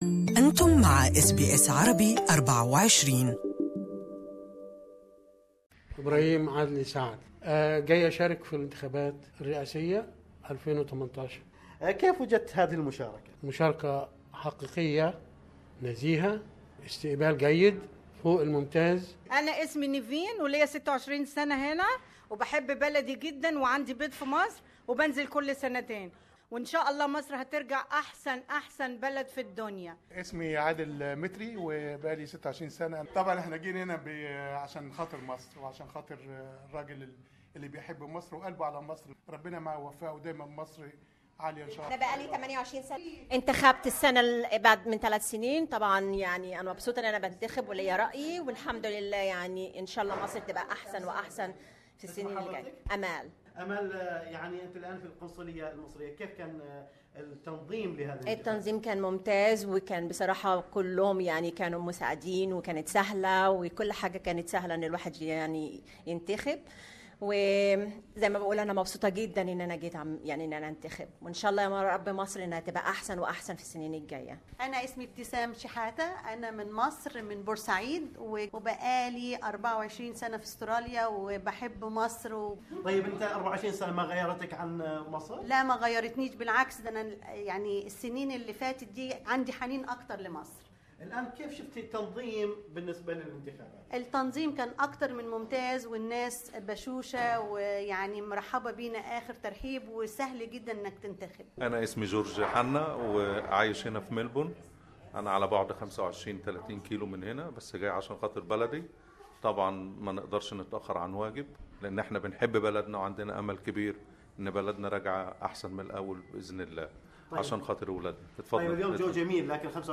وقد حضرت SBS Arabic24 جانبا من مشاركة المصريين في هذه الانتخابات وذلك في مقر القنصلية المصرية في ملبورن.